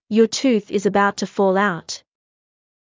ﾕｱ ﾄｩｰｽ ｲｽﾞ ｱﾊﾞｳﾄ ﾄｩ ﾌｫｰﾙ ｱｳﾄ